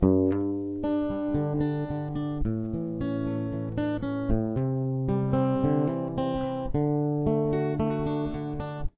Standard Tuning: Acoustic